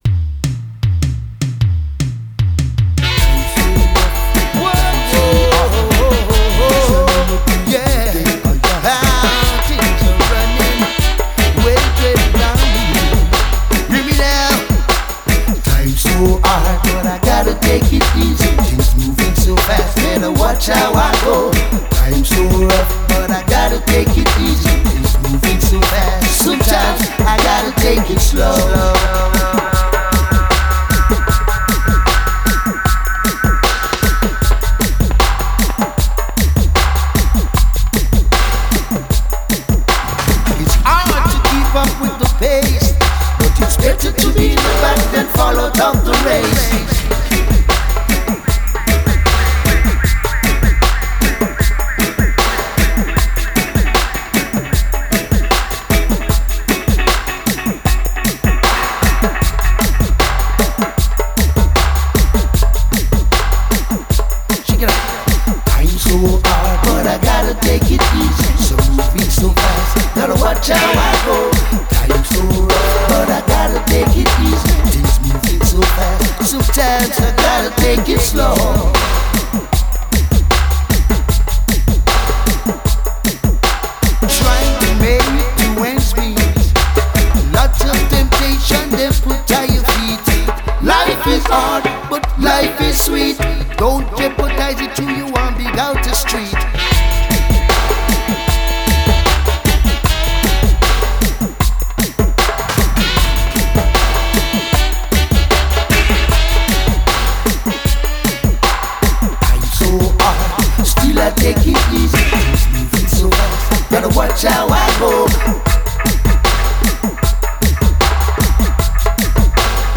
Genre: Reggae, Dub.